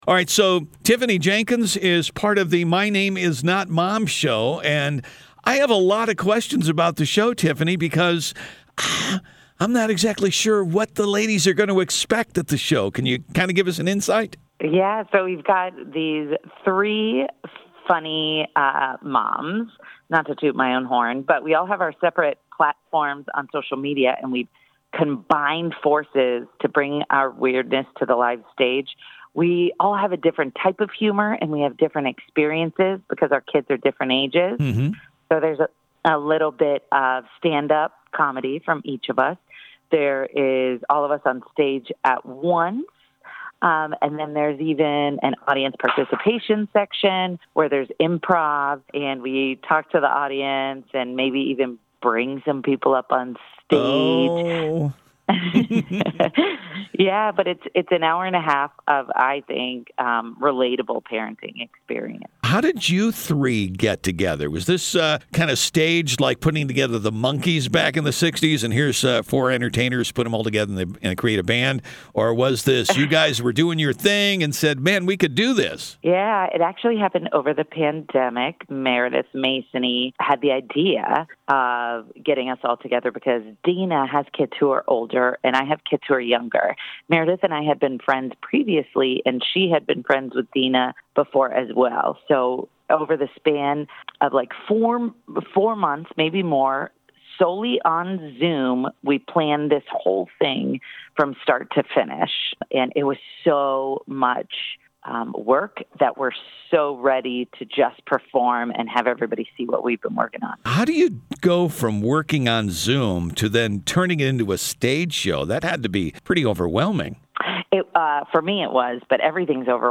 An Intimate Conversation